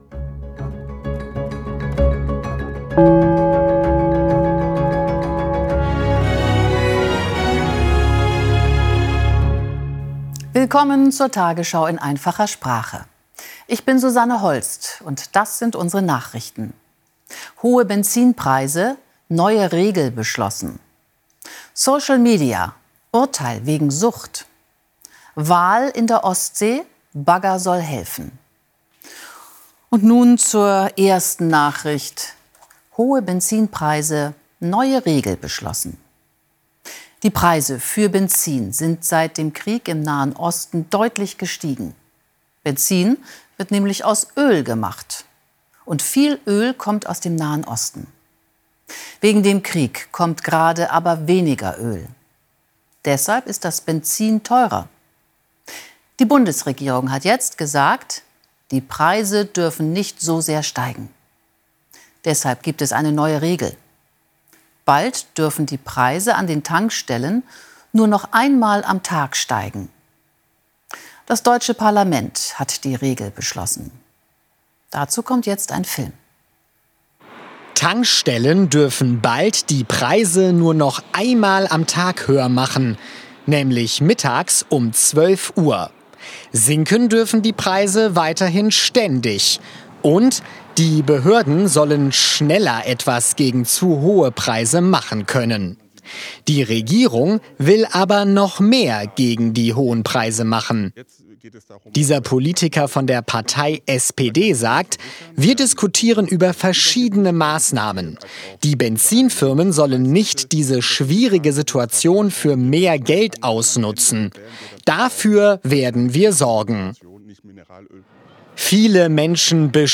Bildung , Nachrichten